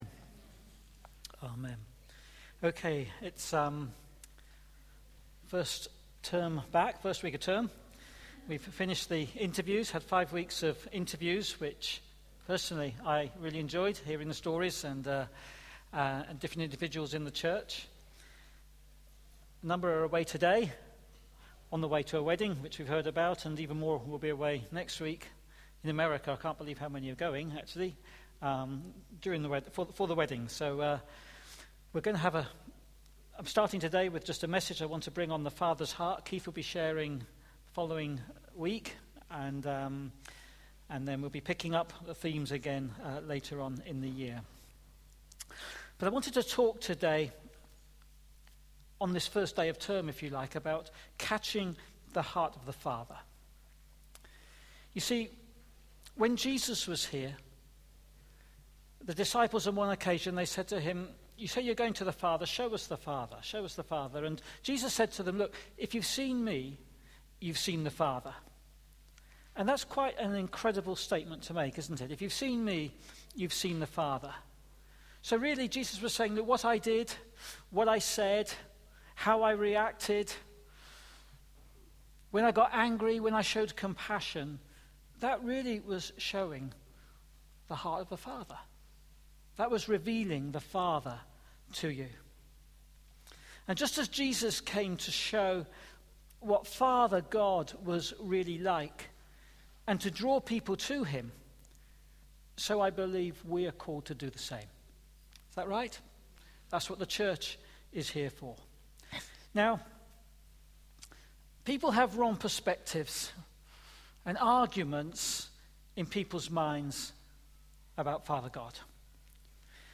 Listen back to all Sunday morning talks below.